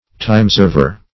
Timeserver \Time"serv`er\, n.